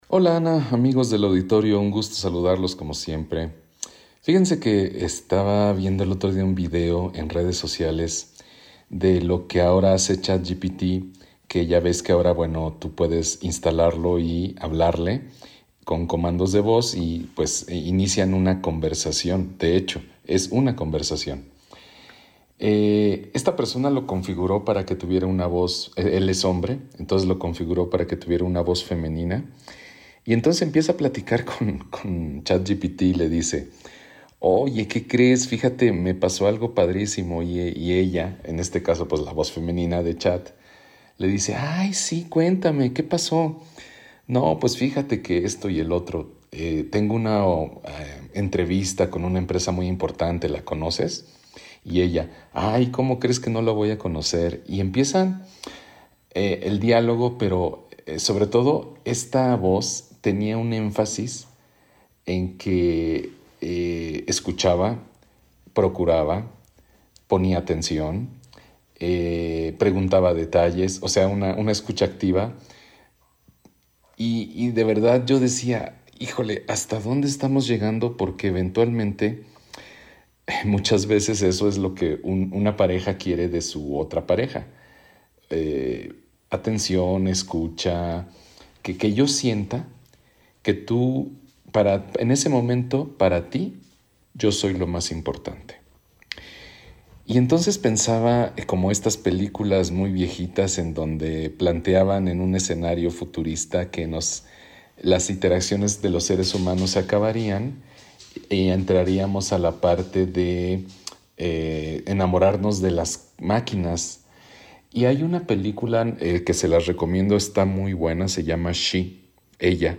su reflexión mensual.